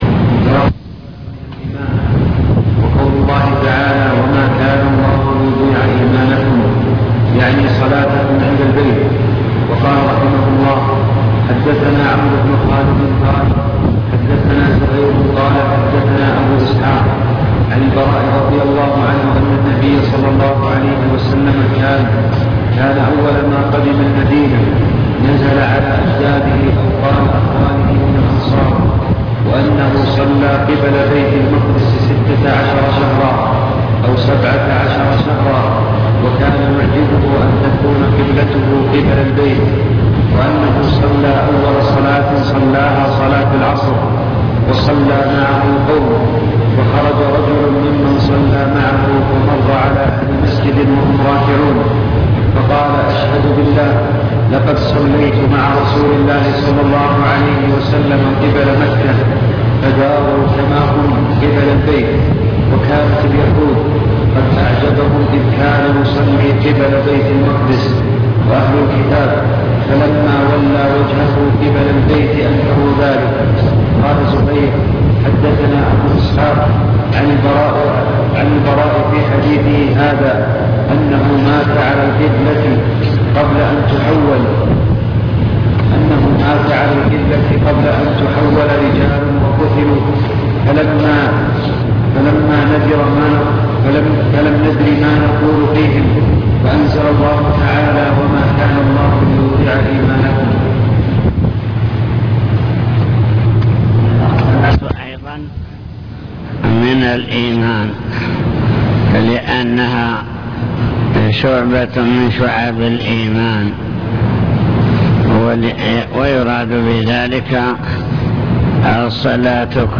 المكتبة الصوتية  تسجيلات - كتب  شرح كتاب الإيمان من صحيح البخاري